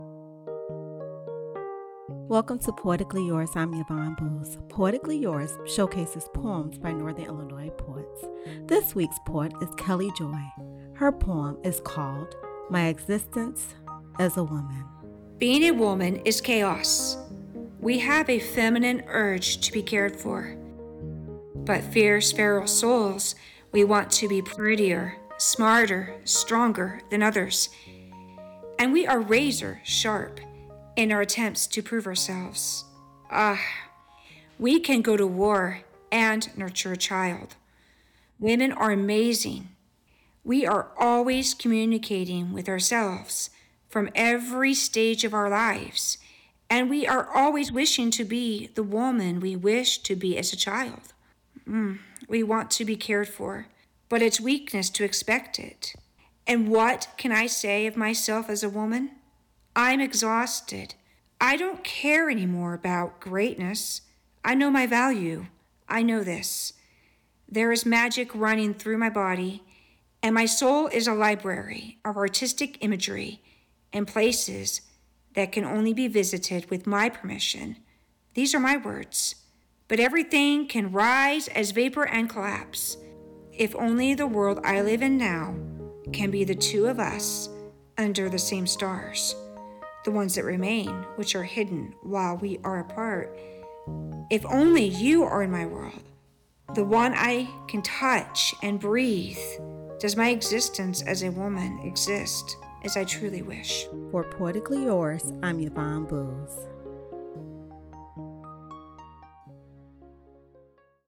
Get ready to hear from northern Illinois’ “prose pros."